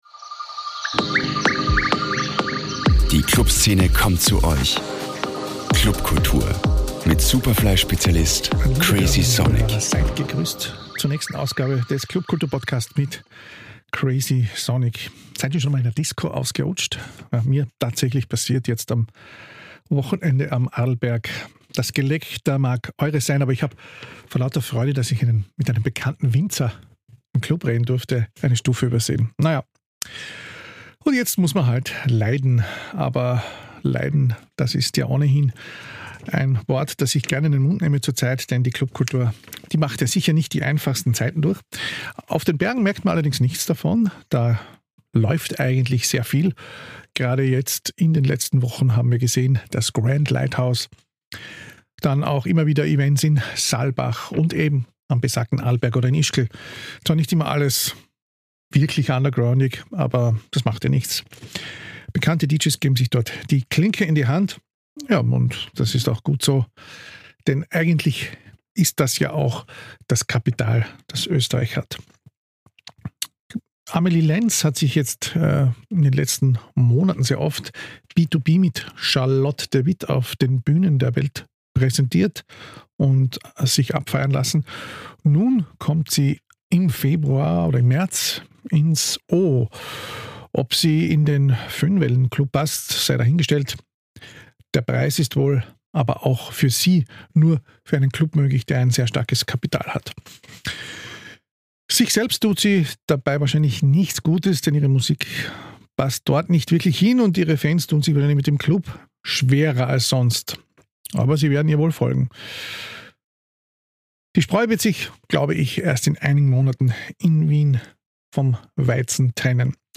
im Gespräch mit zwei Generationen